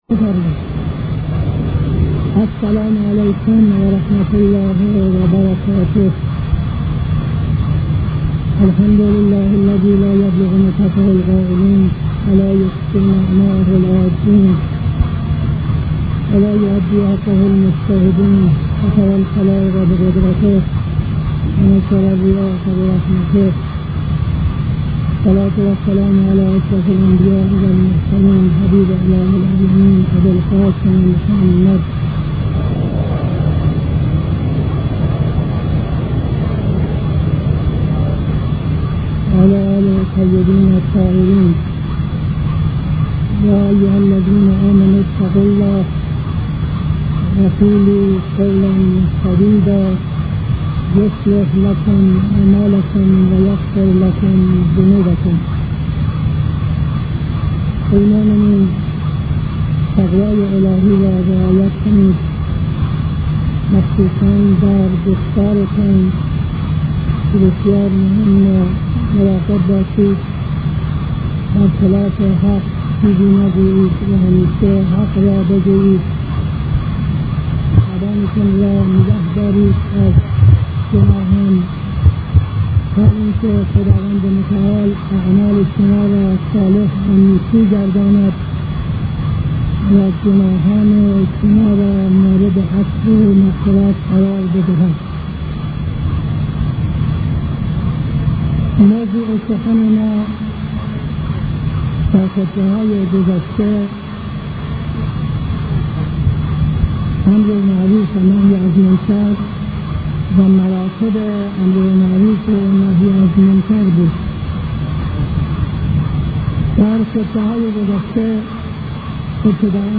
خطبه اول نماز جمعه 12-02-72